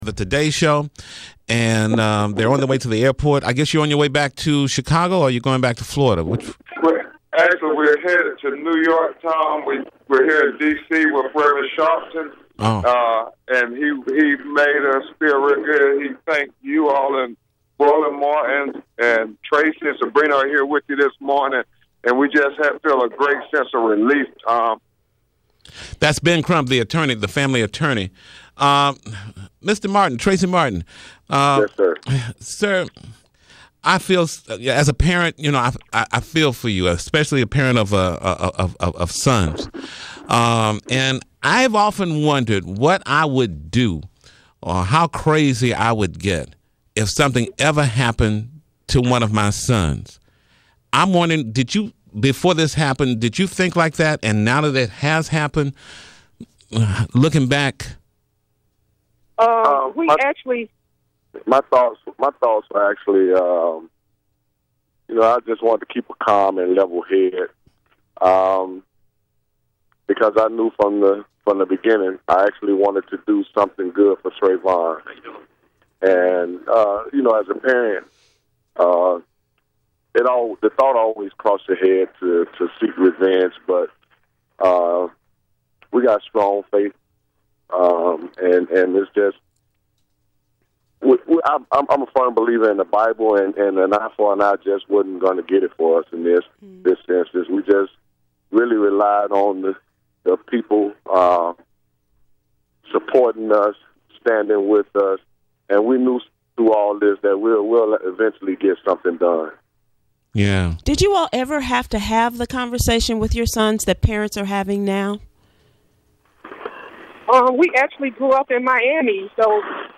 Trayvon’s Parents and Lawyer Talk To Tom Joyner [AUDIO]